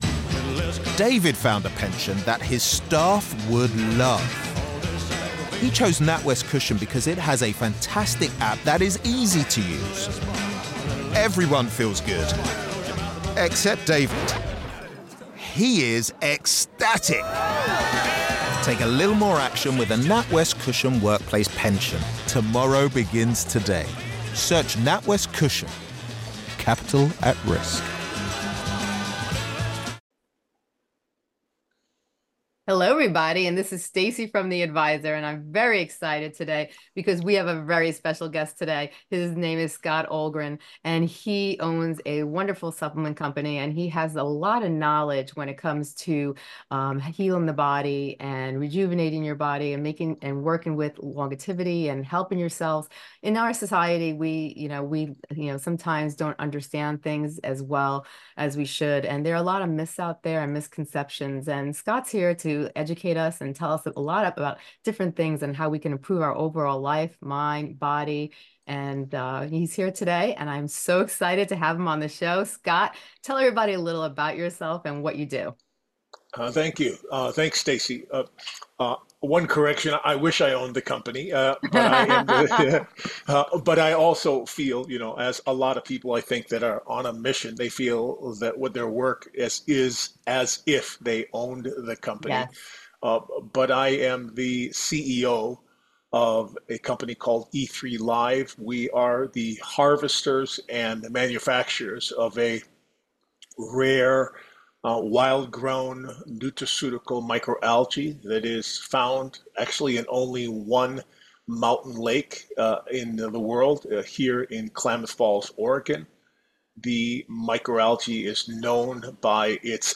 We'll explore what it is, why it matters, and, most importantly, how to improve the quality of your cellular regeneration through more intelligent food choices. Don't miss this eye-opening conversation on what could be the missing key to your health and longevity. https